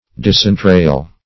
Search Result for " disentrail" : The Collaborative International Dictionary of English v.0.48: Disentrail \Dis`en*trail"\, v. t. To disembowel; to let out or draw forth, as the entrails.